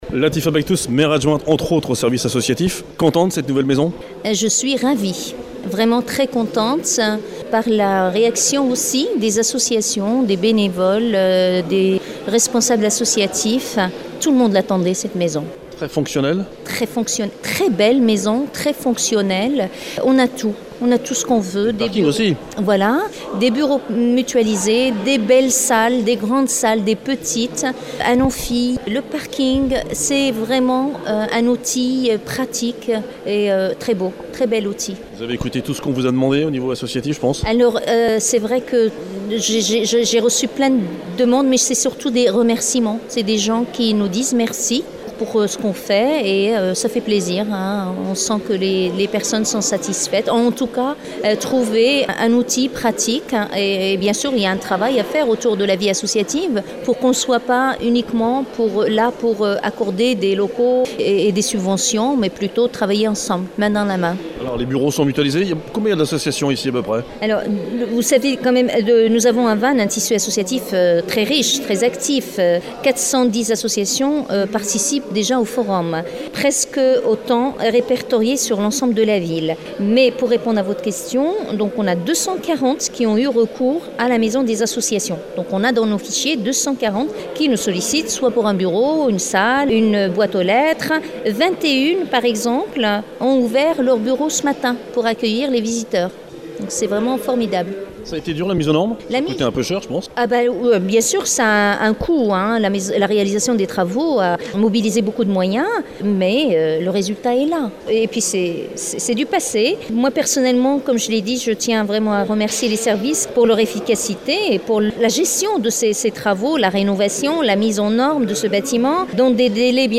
Inauguration de la nouvelle Maison des Associations, à Vannes, quartier de Kercado, rue Guillaume Le Barz,  samedi 23 janvier, la Maison des Associations, le nouveau lieu de vie des associations vannetaises qui remplace le site de la Tannerie, devenu vétuste et ne répondant plus aux exigences d’accessibilité.
Interviews de David Robo – Maire de Vannes
Latifa Bakhtous – Maire Adjointe à la Vie Associative de Vannes